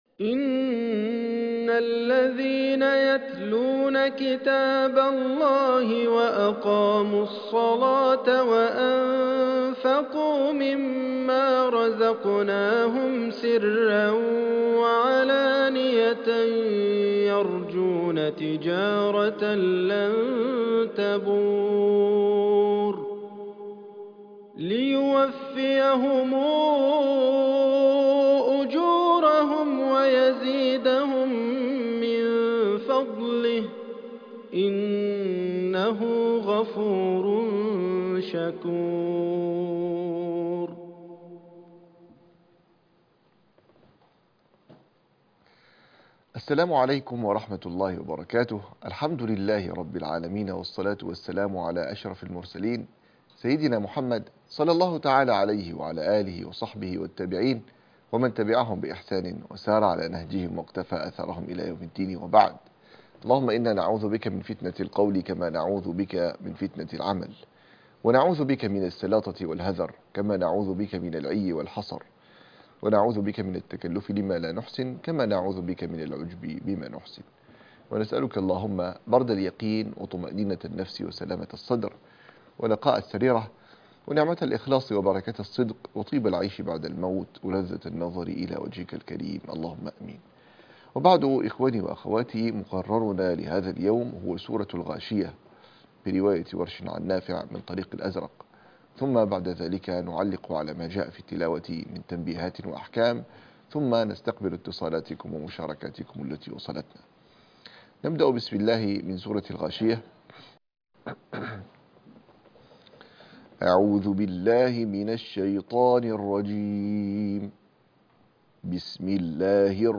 مقرر التلاوة سورة الغاشية -الحلقة الخامسة عشر -مقرأ ورش 2